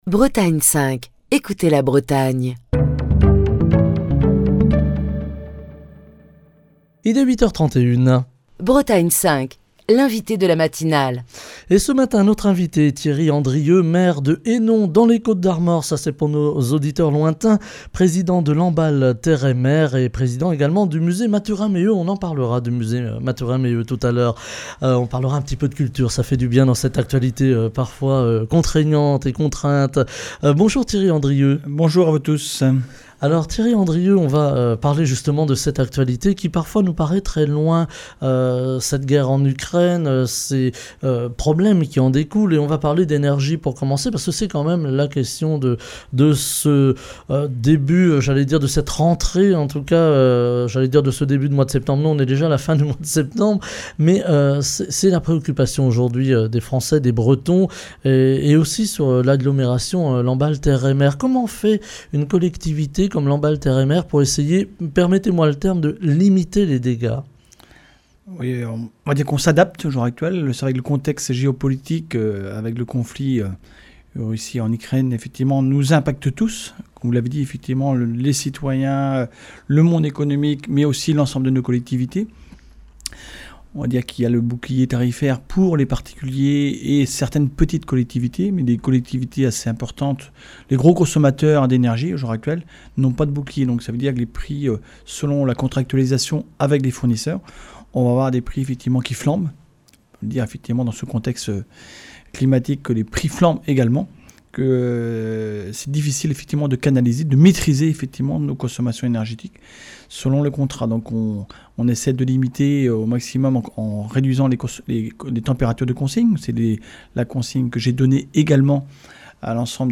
Comment les collectivités territoriales font face à la crise énergétique et aux conséquences budgétaires liées à l'augmentation du coût de l'énergie ? Comment poursuivre le développement des territoires tout en réalisant des économies sur les budgets ? Ce matin, nous nous penchons sur la communauté d'agglomérations de Lamballe Terre et Mer avec son président, Thierry Andrieux, également maire de Hénon (22) et président du Musée Mathurin Méheut, qui est l'invité de la matinale.